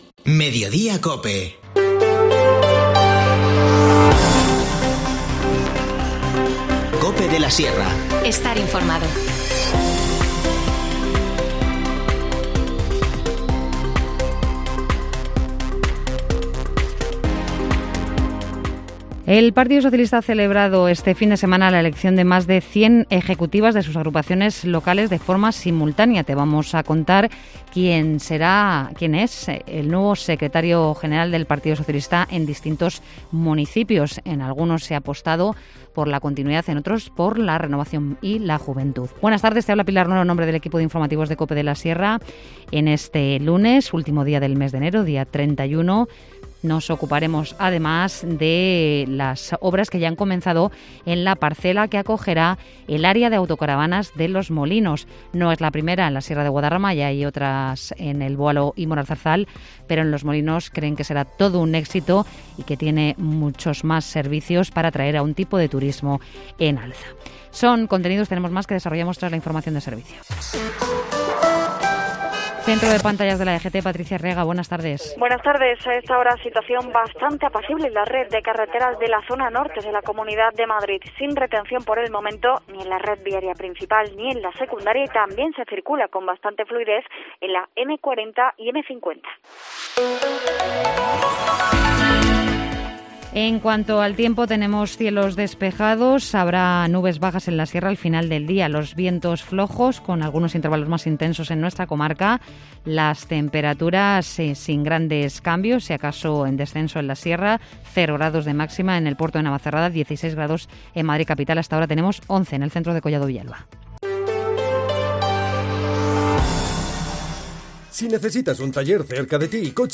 Informativo Mediodía 31 enero